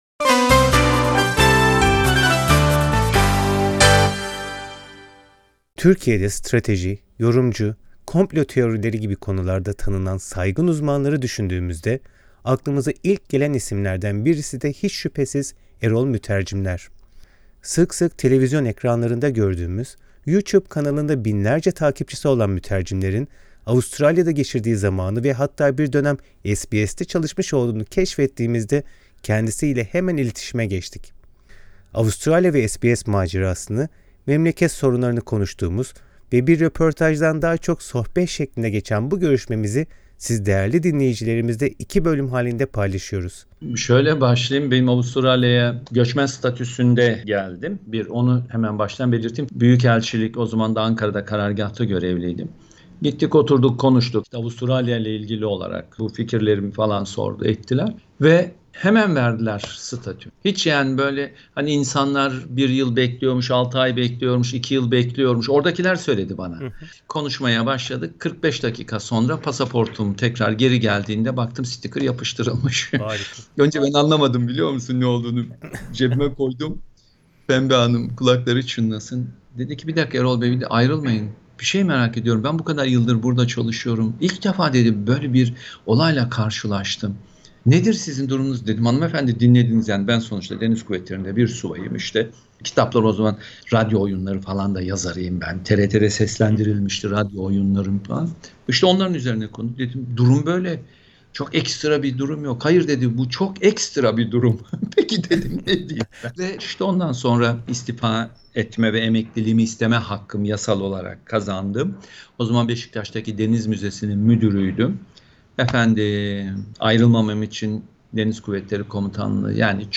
Yorumcu Erol Mütercimler'le yaptığımız röportajın birinci bölümünde Avustralya'da geçirdiği zamanı ve SBS'teki deneyimlerini konuştuk.